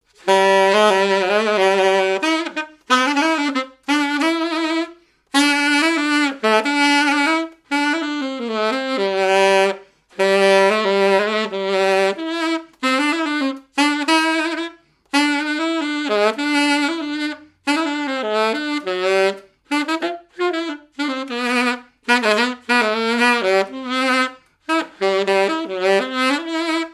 danse : polka
Genre strophique
activités et répertoire d'un musicien de noces et de bals
Pièce musicale inédite